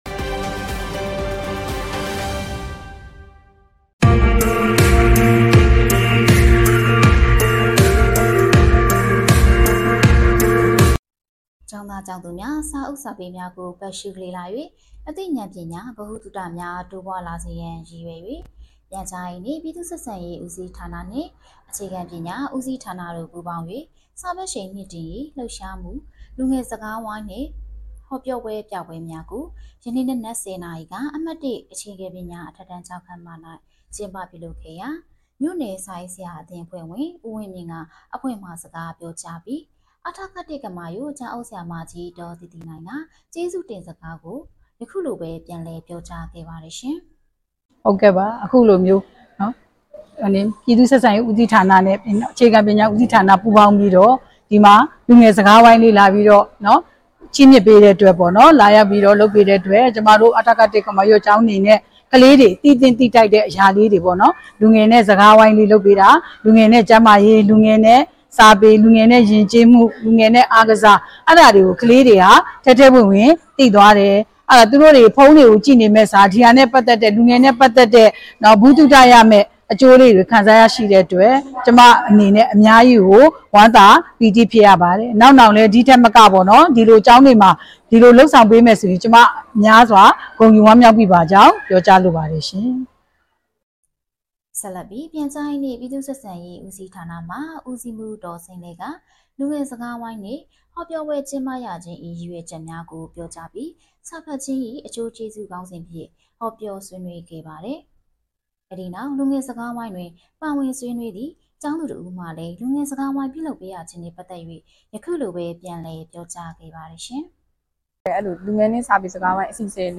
ကမာရွတ်မြို့နယ်စာဖတ်ရှိန်မြှင့်တင်ရေးအခမ်းအနား